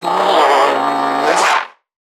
NPC_Creatures_Vocalisations_Infected [118].wav